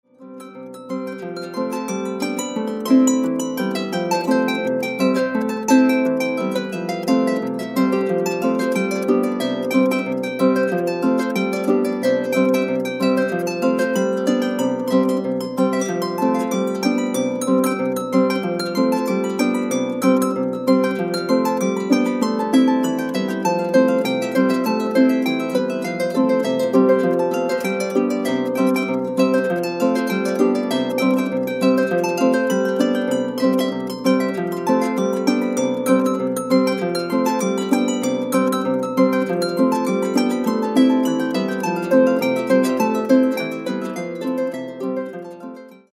(Celtic harp)  2'492.58 MB1.70 Eur